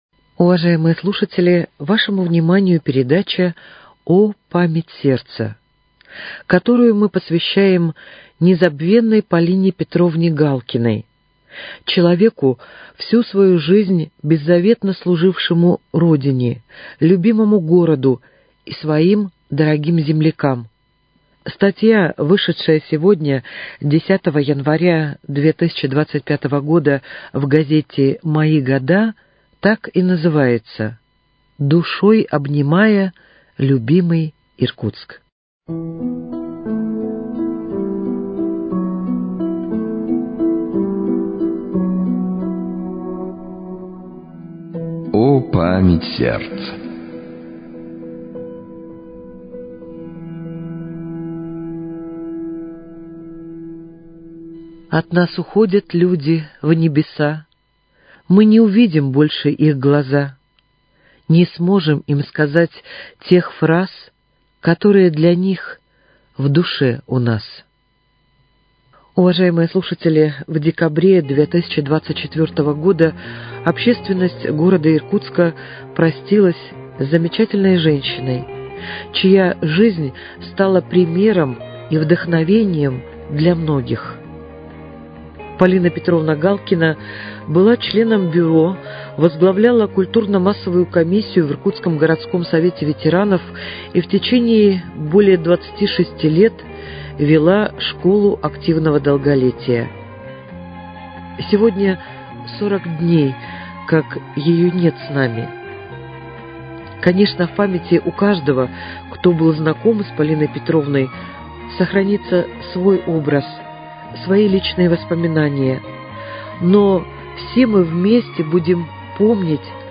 передача её памяти, составленная из воспоминаний о ней соратников по общественной работе и друзей.